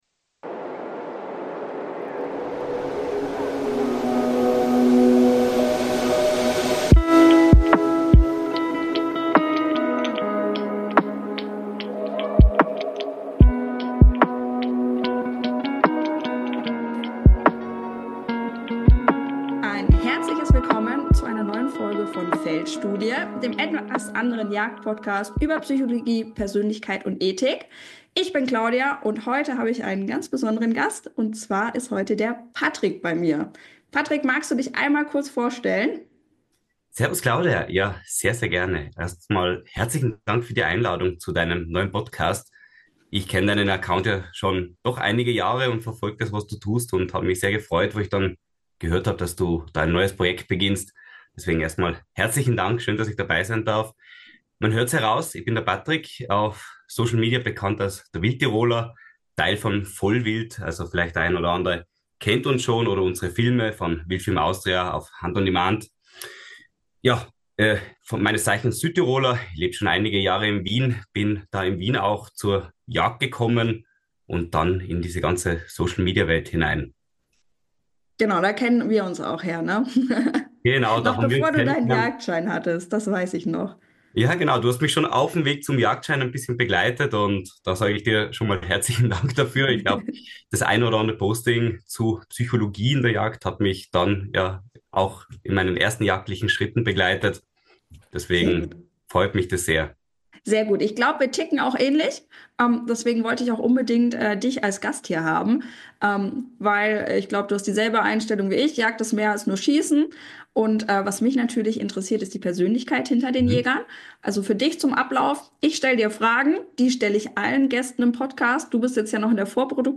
Ein Gespräch über Werte, Wandel und die Verantwortung, Jagd nicht nur auszuüben – sondern auch zu erklären.